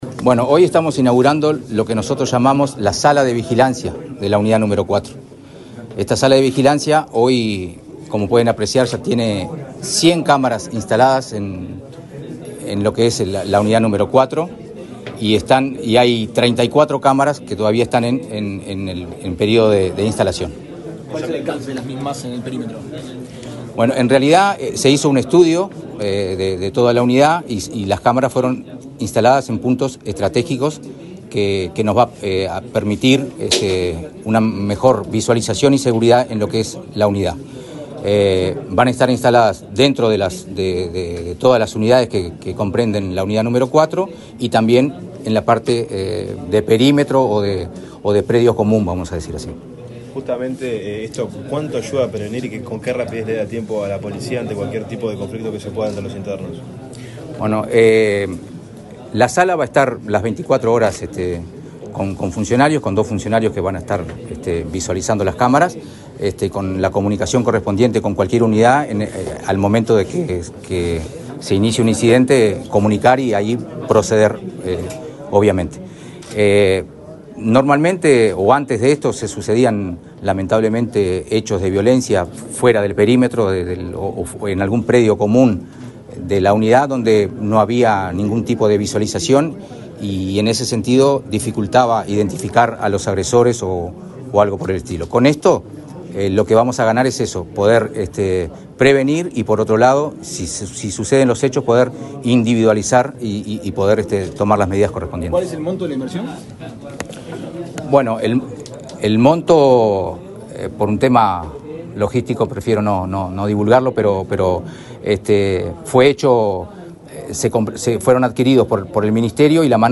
Declaraciones del subdirector administrativo del INR, Gustavo de los Santos
Declaraciones del subdirector administrativo del INR, Gustavo de los Santos 19/02/2025 Compartir Facebook X Copiar enlace WhatsApp LinkedIn El subdirector nacional administrativo del Instituto Nacional de Rehabilitación (INR), Gustavo de los Santos, dialogó con la prensa, luego de participar en el acto de inauguración de una sala de videovigilancia en el complejo n.° 4, en Santiago Vázquez.